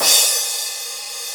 RS CRASH.wav